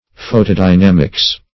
Search Result for " photodynamics" : The Collaborative International Dictionary of English v.0.48: Photodynamics \Pho`to*dy*nam"ics\, n. [Photo- + dynamics.]
photodynamics.mp3